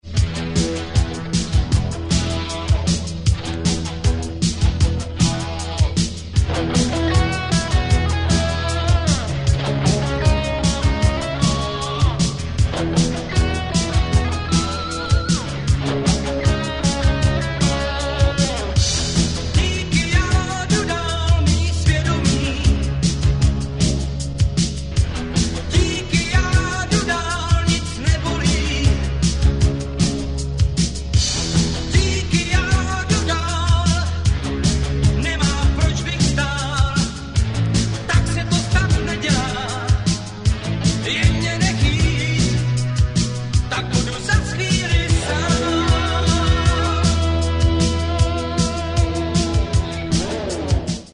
oblíbená plzeňská poprocková kapela, založená roku 1981.